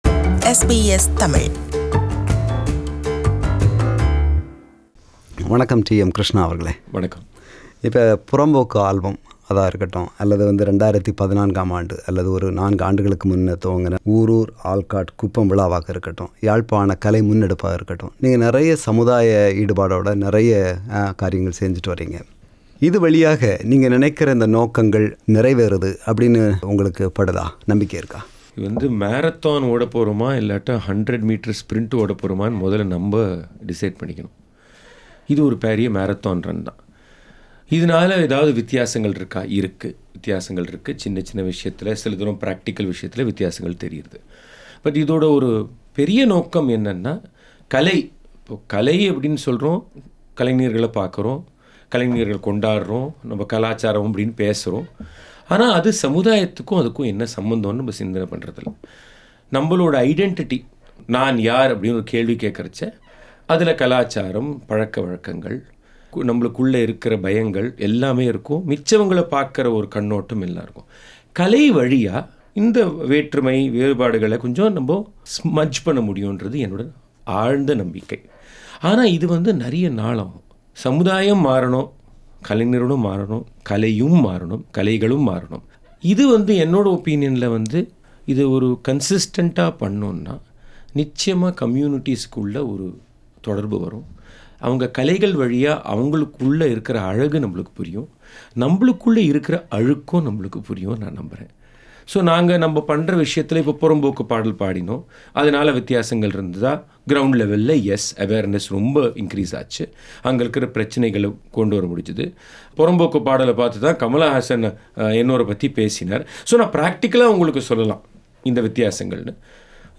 Interview with T.M. Krishna – Part 1